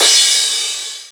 Index of /90_sSampleCDs/Club_Techno/Percussion/Cymbal
Crash_1.wav